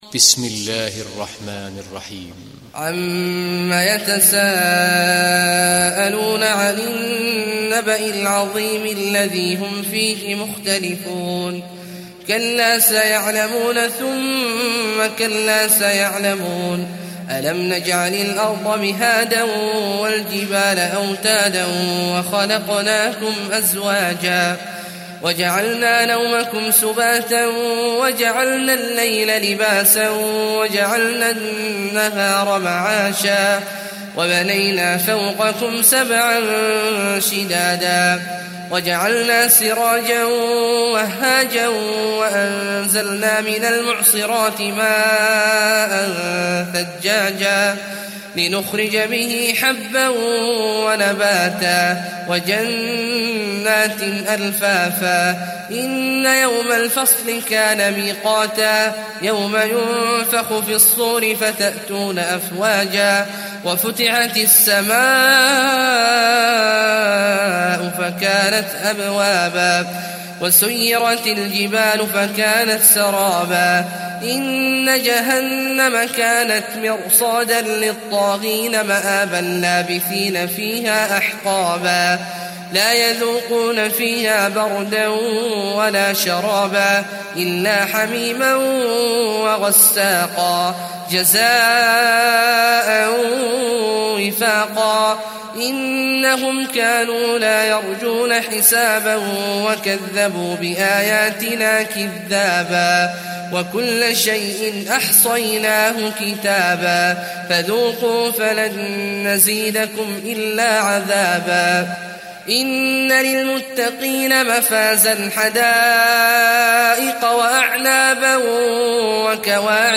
Surat An Naba Download mp3 Abdullah Awad Al Juhani Riwayat Hafs dari Asim, Download Quran dan mendengarkan mp3 tautan langsung penuh